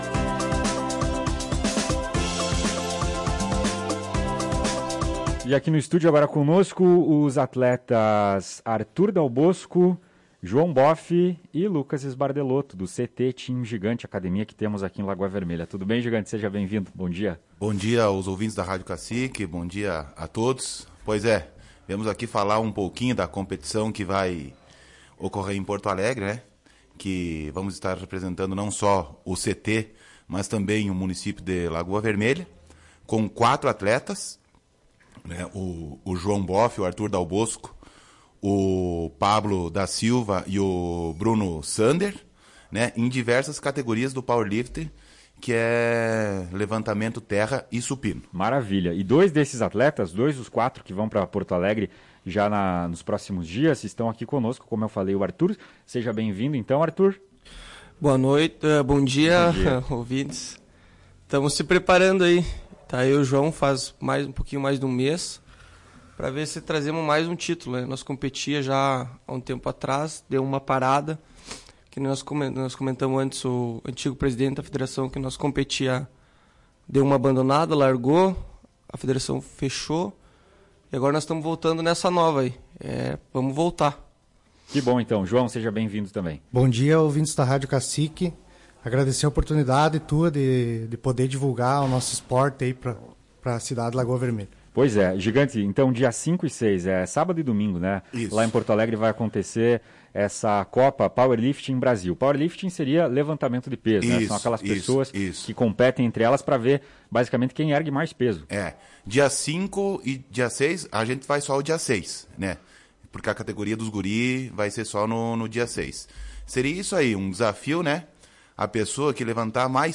Em entrevista à Tua Rádio Cacique